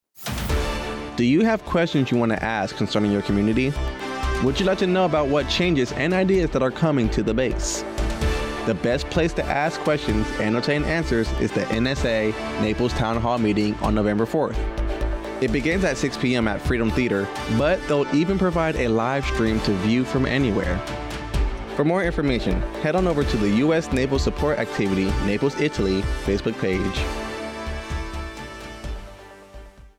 AFN Naples Radio Spot - NSA Naples Town Hall